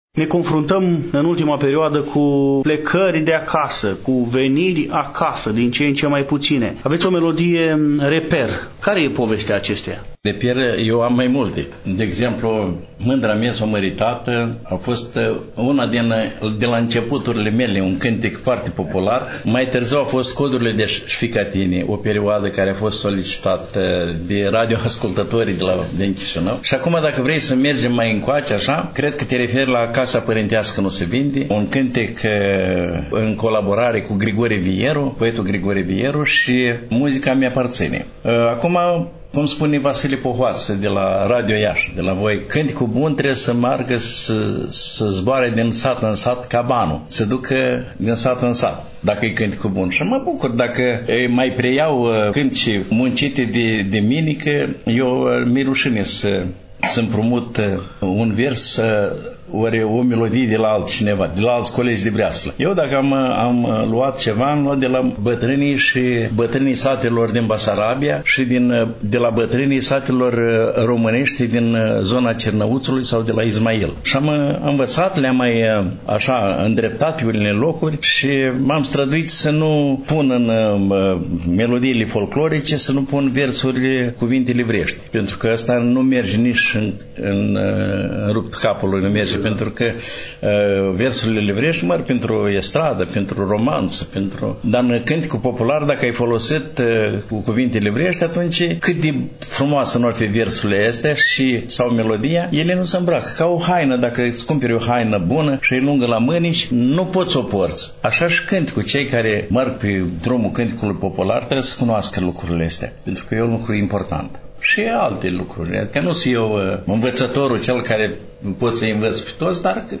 5_Dialog-Interpret-de-Muzica-Populara-Mihai-Ciobanu-5-20.mp3